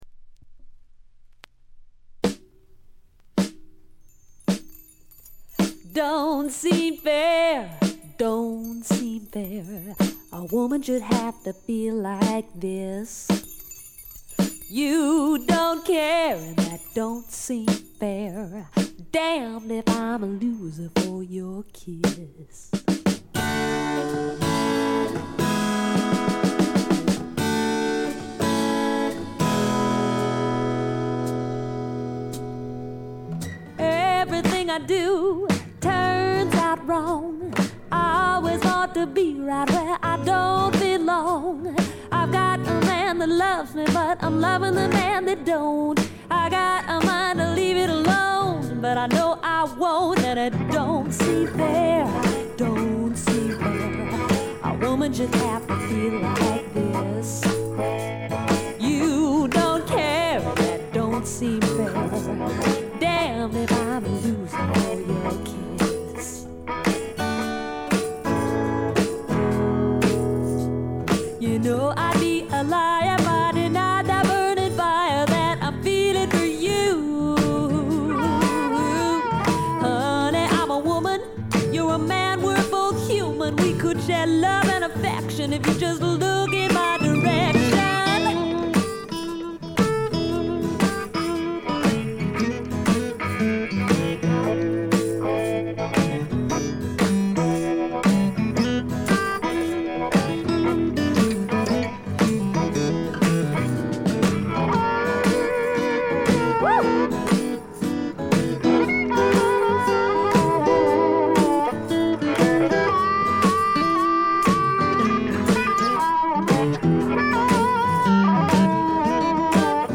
わずかなノイズ感のみ。
気を取り直して・・・これはシアトル産の自主制作盤で、知られざるAOR系女性シンガーソングライターの快作です。
試聴曲は現品からの取り込み音源です。
Recorded At - Sea-West Studios, Seattle